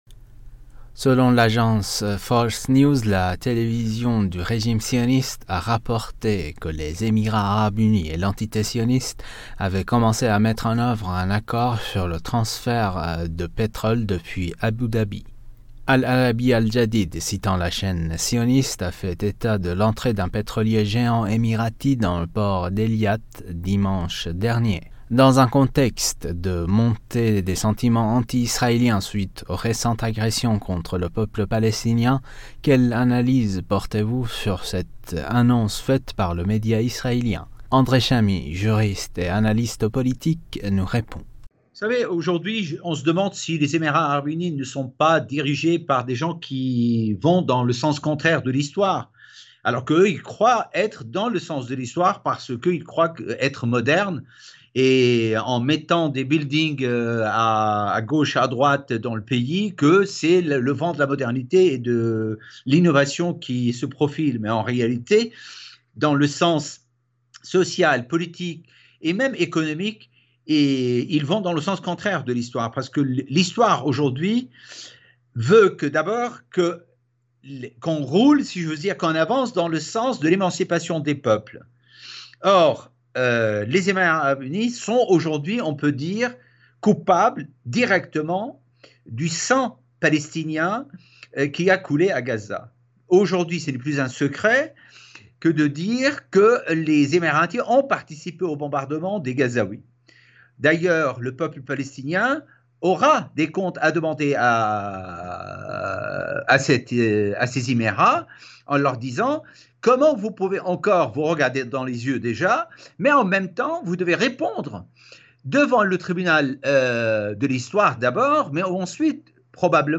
juriste international s’exprime sur le sujet.